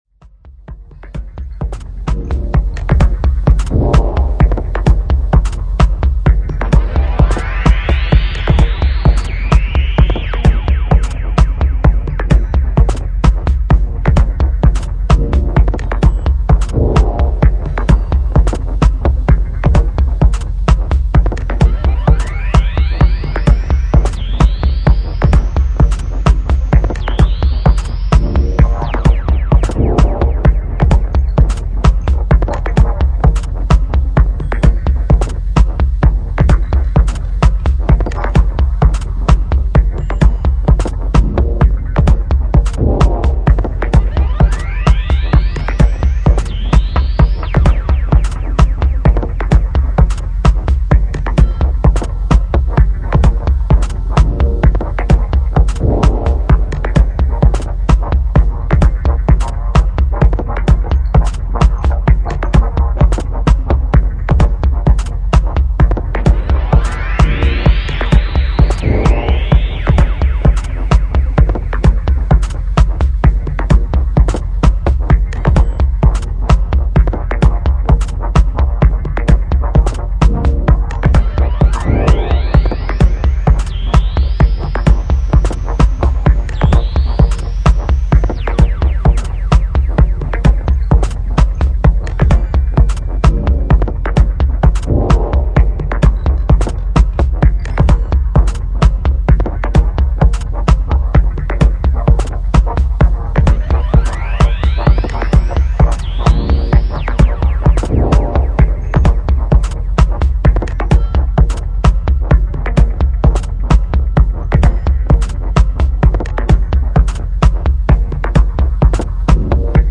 The atmospheric aesthetic of the LP
a soulful connection with enlightment.
with the signals slowly fading as an escapism.